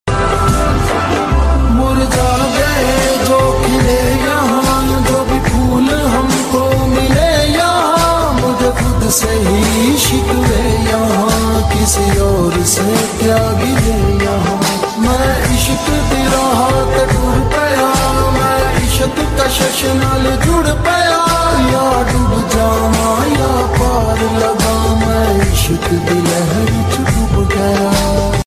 Nature's masterpiece: mountains meeting waterfalls sound effects free download